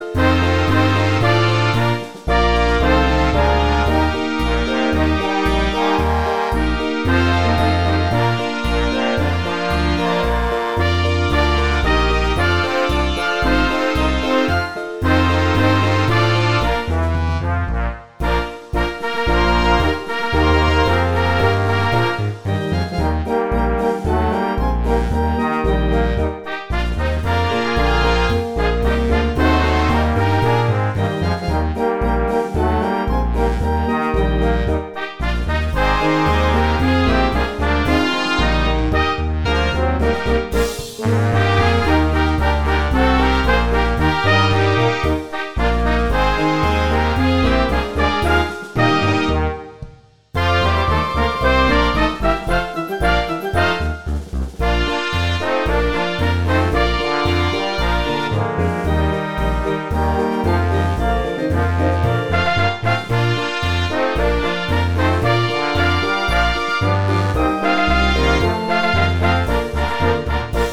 Gattung: Stimmungs-Potpourri
Besetzung: Blasorchester